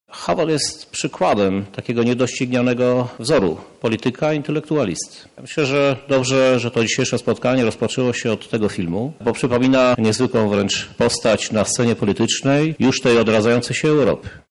Z jednej strony czterech rozmówców, a z drugiej cała sala ludzi.